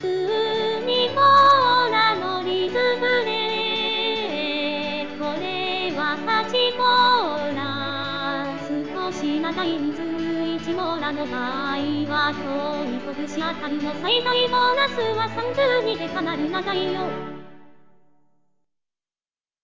「8ビート(シンプル)2」　ss